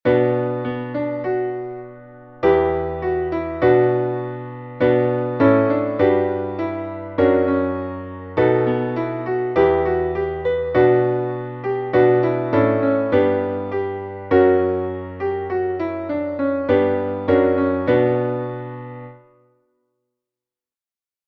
μελωδία και συγχορδίες, Bmin